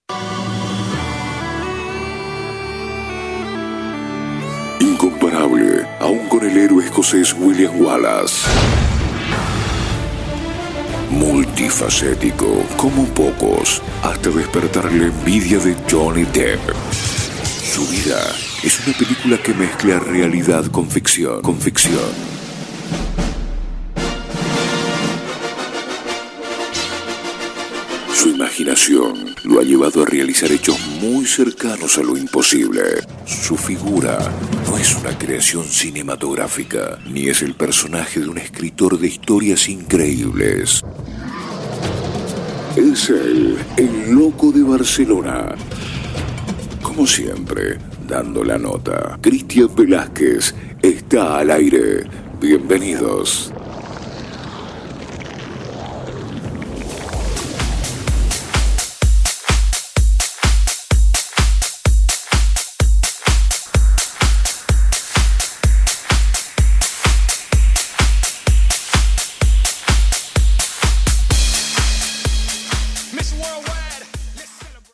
Careta del programa i tema musical
Musical